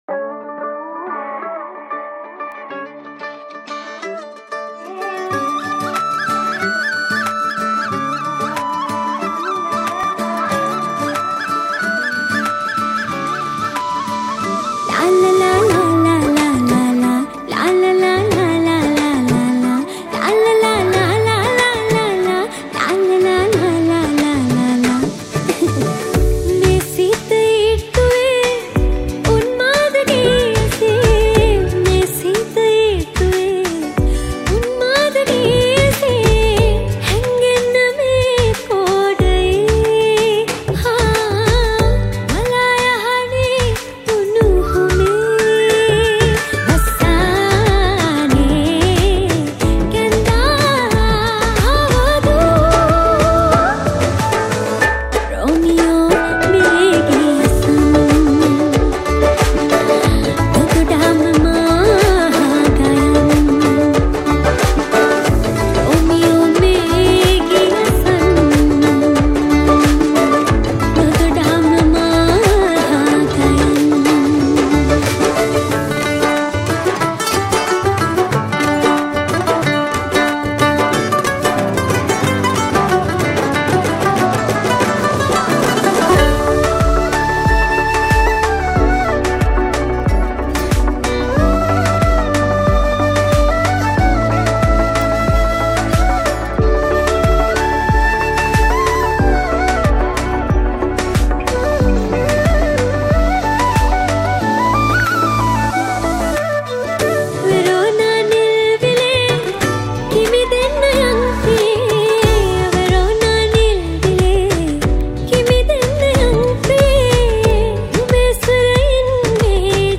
Mandolin
Keyboards
Percussion
Guitar
Flute